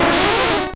Cri de Kokiyas dans Pokémon Or et Argent.